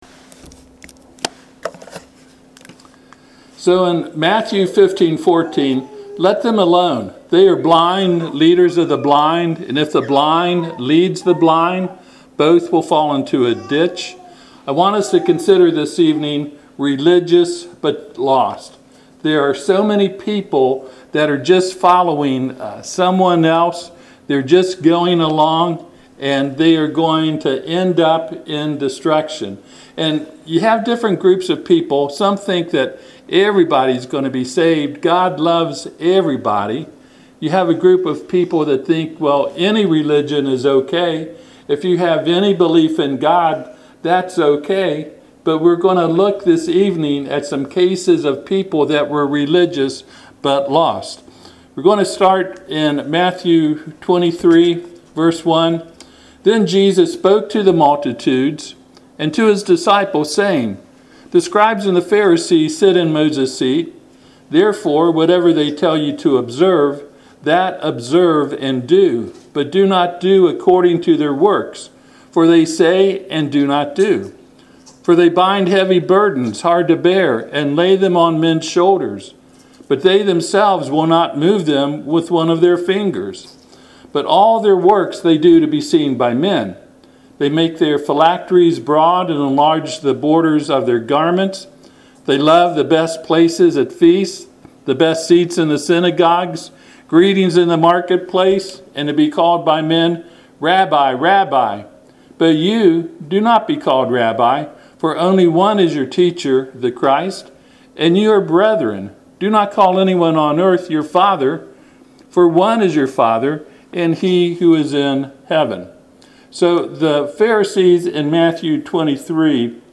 Matthew 15:14 Service Type: Sunday PM https